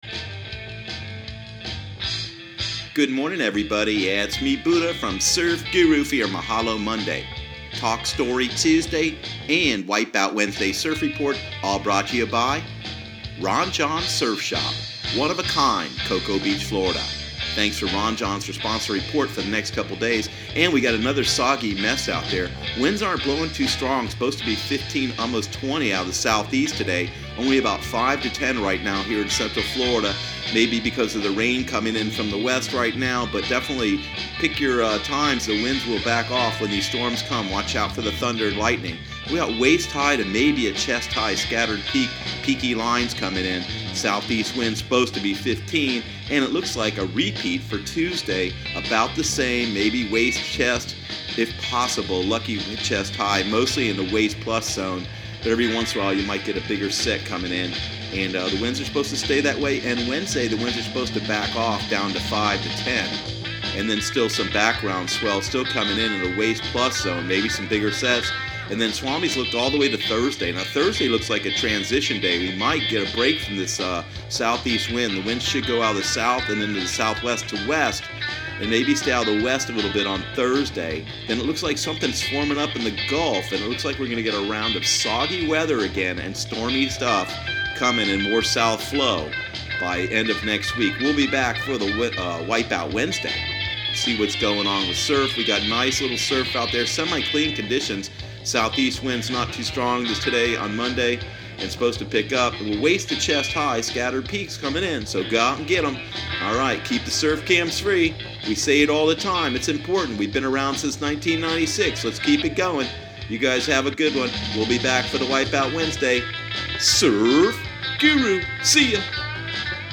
Surf Guru Surf Report and Forecast 05/21/2018 Audio surf report and surf forecast on May 21 for Central Florida and the Southeast.